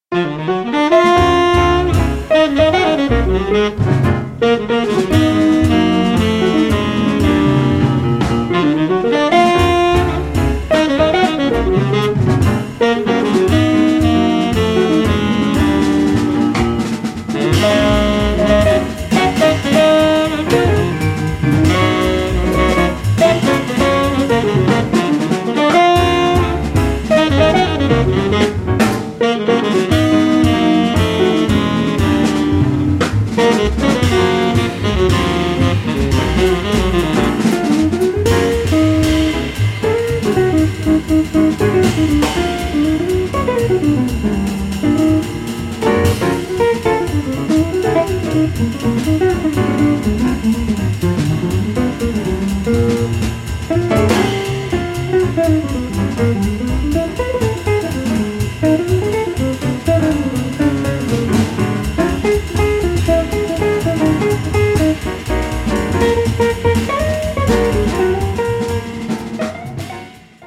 Guitare
Ténor
Piano
Contrebasse
Batterie